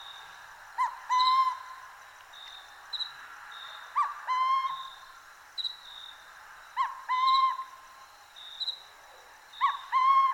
Burrowing Owl